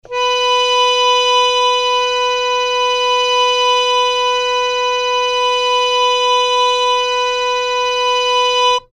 harmonium
B4.mp3